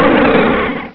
sovereignx/sound/direct_sound_samples/cries/relicanth.aif at master
relicanth.aif